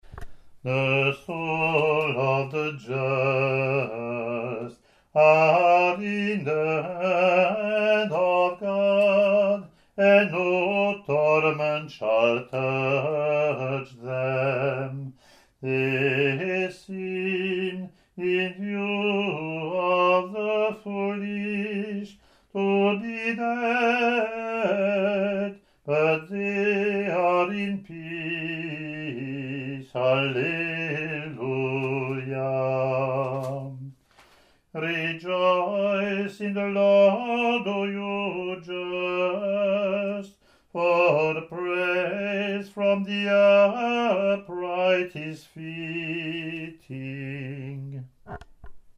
English antiphon – English verse , Latin antiphon)